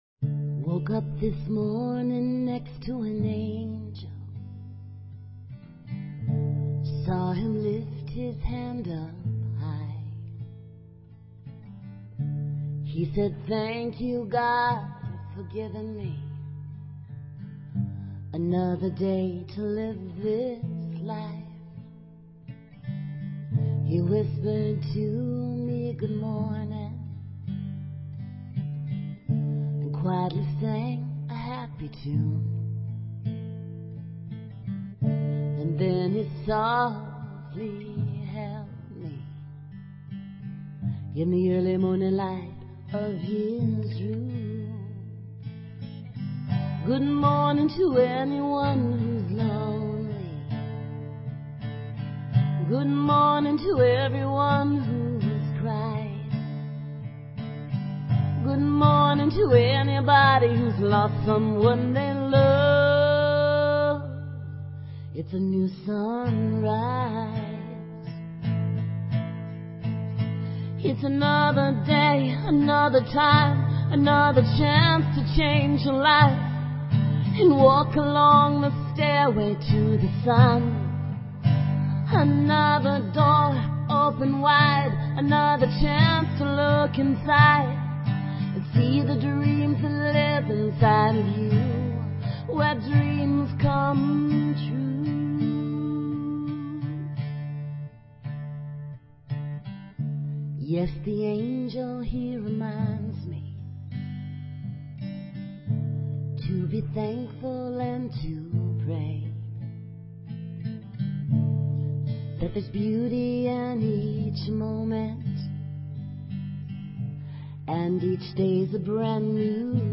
Talk Show Episode, Audio Podcast, Connecting_Spirit_Together and Courtesy of BBS Radio on , show guests , about , categorized as